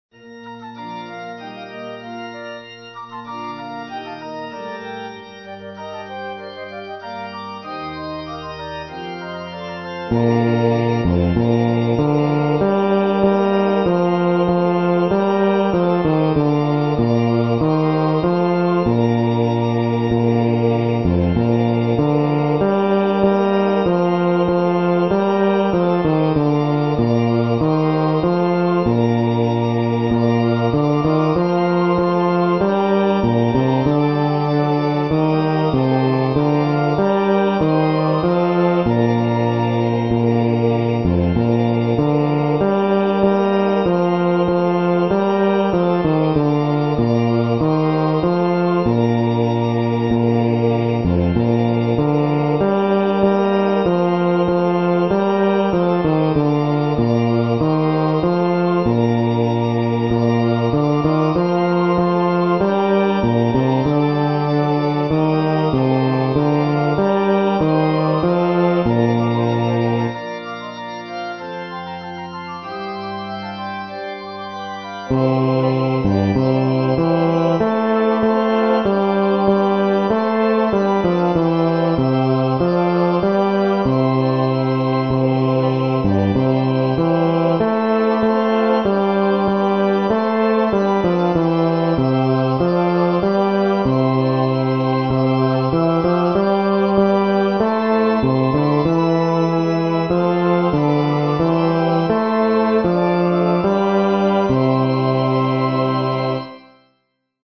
バス（フレットレスバス音）